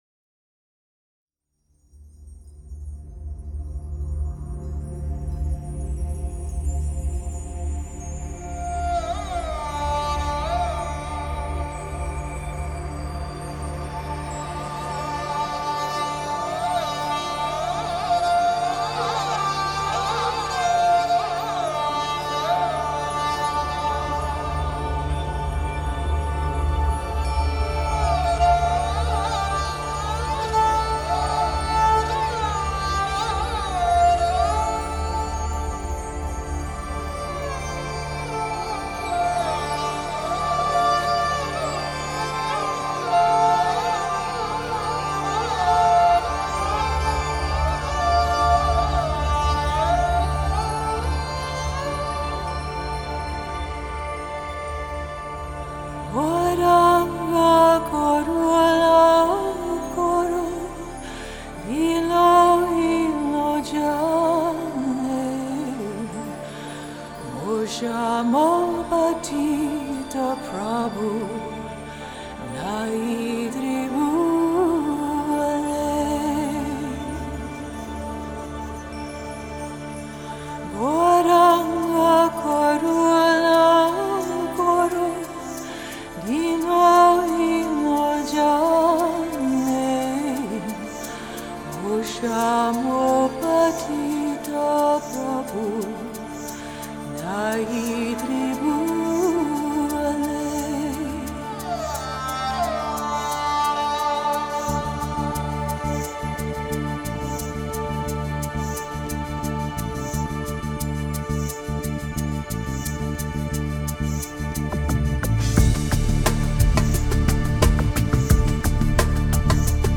американский нью-эйдж дуэт из Сан-Франциско
индуистскую религиозную музыку в современной обработке